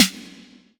• Airy Sizzle Steel Snare Drum Sample B Key 12.wav
Royality free snare single hit tuned to the B note.
airy-sizzle-steel-snare-drum-sample-b-key-12-dgJ.wav